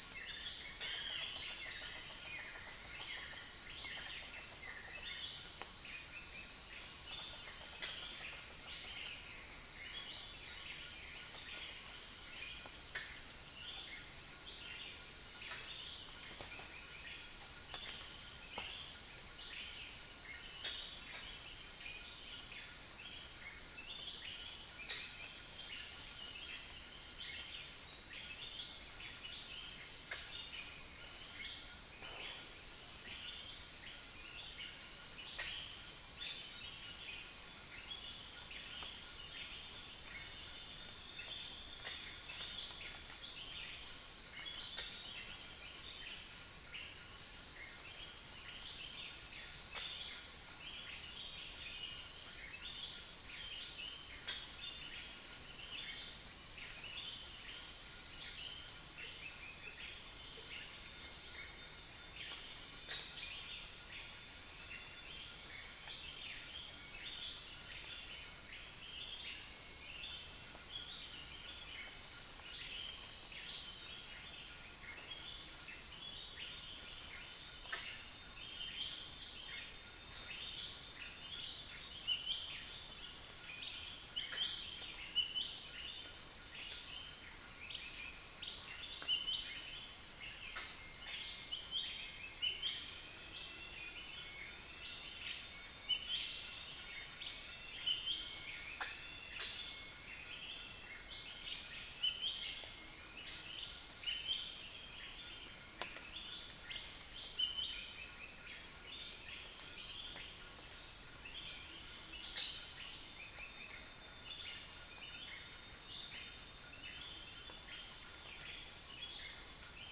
Upland plots dry season 2013
Stachyris maculata
Buceros rhinoceros
Mixornis gularis
Cuculus micropterus
Pycnonotus simplex